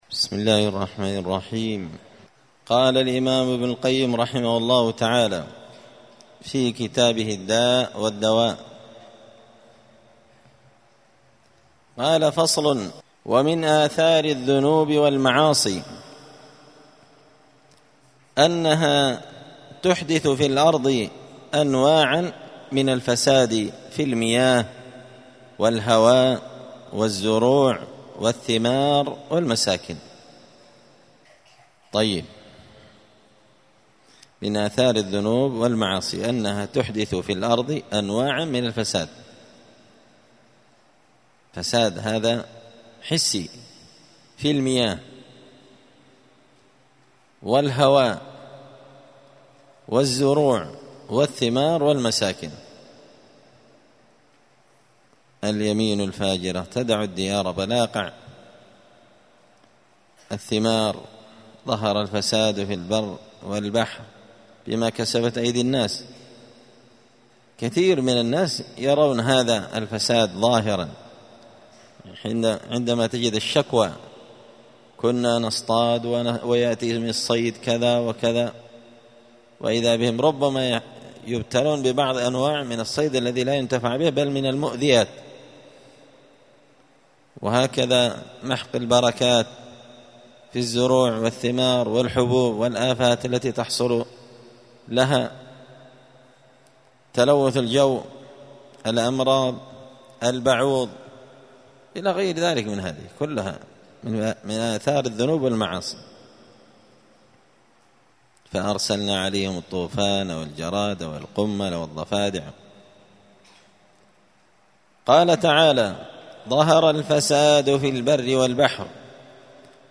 دار الحديث السلفية بمسجد الفرقان بقشن المهرة اليمن 📌الدروس الأسبوعية